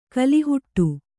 ♪ kalihuṭṭu